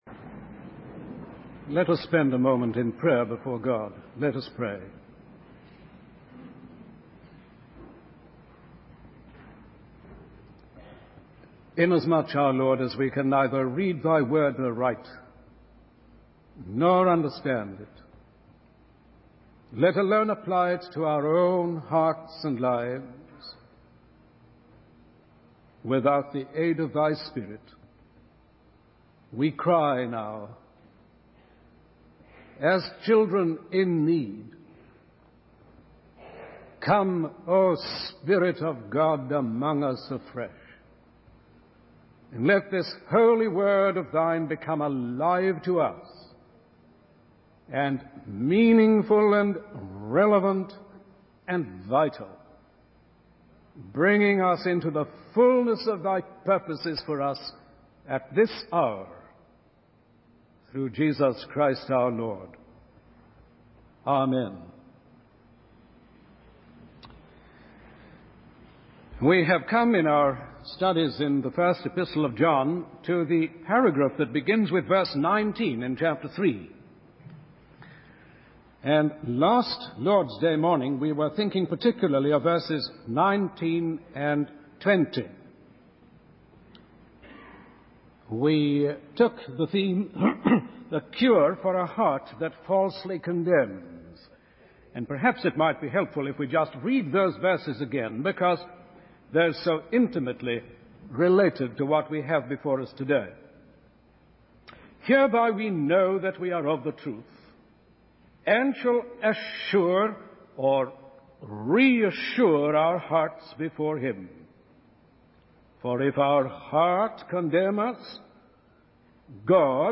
In this sermon, the preacher focuses on the passage from 1 John 3:19-21. The sermon begins by discussing the cure for a heart that falsely condemns, emphasizing the importance of reassurance and confidence in God. The preacher then moves on to the concept of new horizons, urging the congregation to see the new possibilities and blessings that God has in store for them.